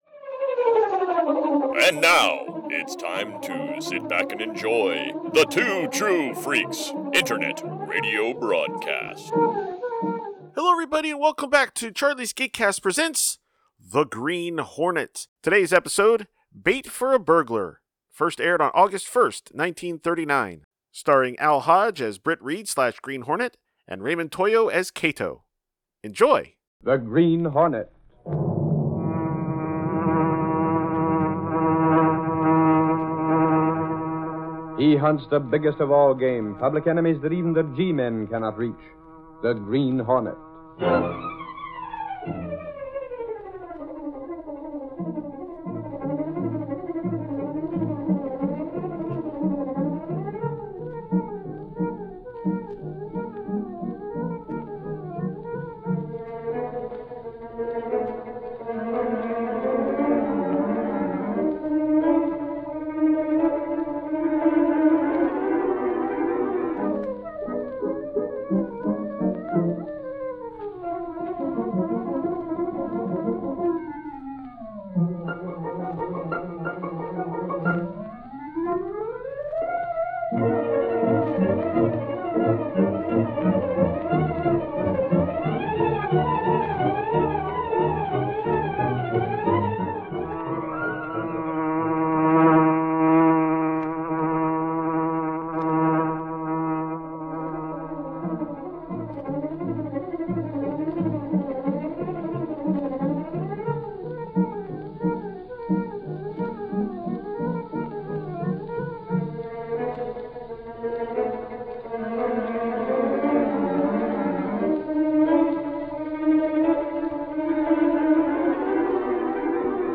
See author's posts Tagged as: Kato , The Daily Sentinel , The Black Beauty , radio series , The Green Hornet , Britt Reid . email Rate it 1 2 3 4 5